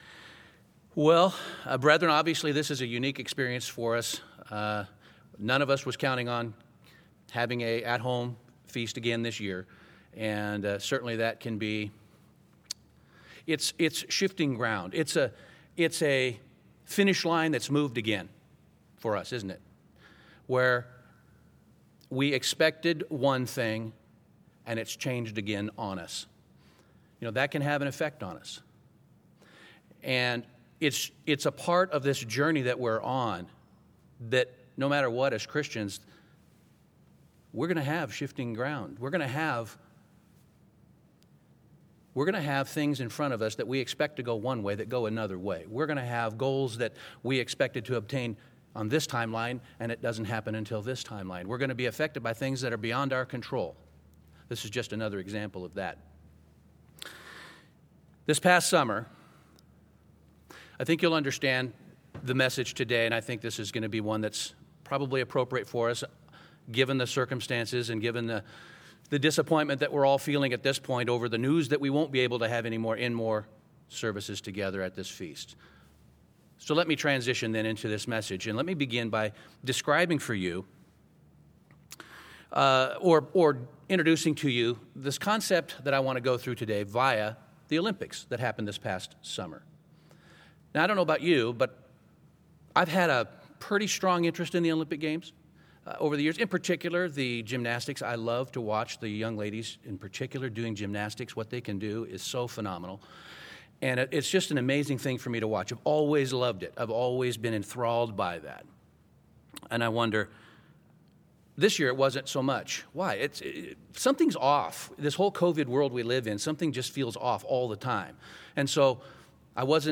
This sermon was given at the Bend-Redmond, Oregon 2021 Feast site.